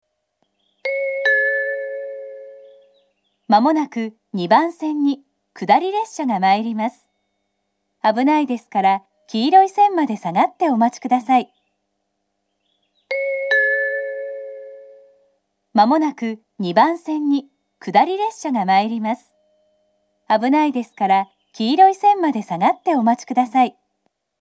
２番線下り接近放送